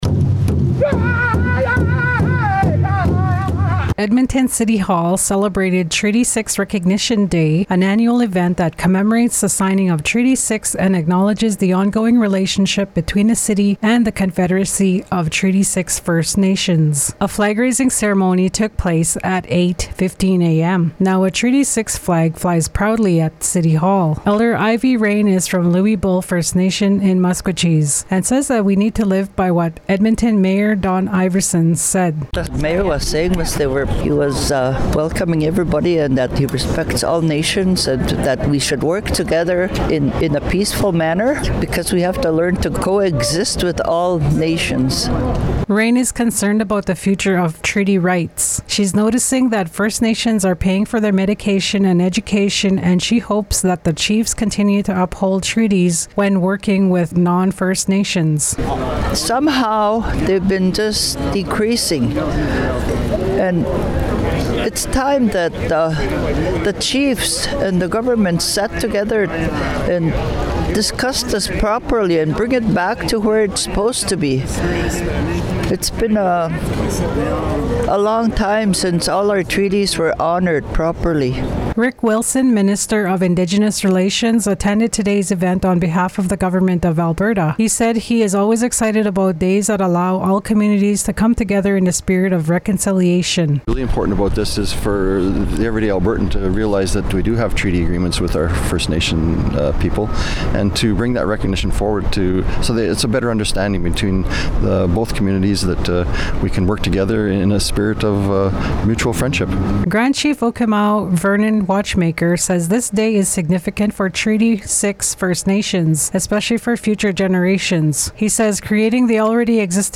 A flag raising ceremony took place at 8:15 a.m. The Treaty 6 flag now flies proudly at City Hall.
Radio_doc_Treaty6RecognitionDay_City-Hall_02.mp3